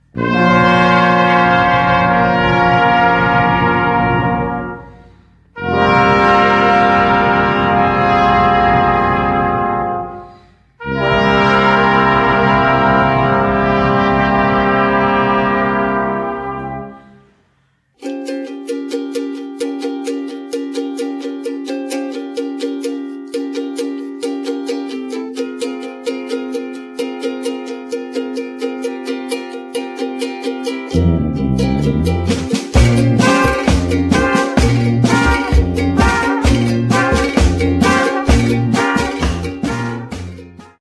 ukrywa się nagrany w Oaxaca w Meksyku
w bogatych aranżacjach na instrumenty dęte